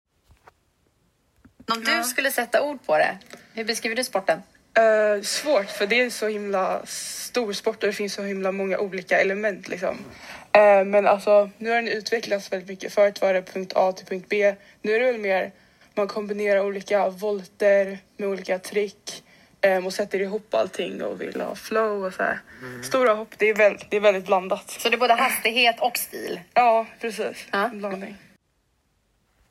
Denna röst tillhör en av dem!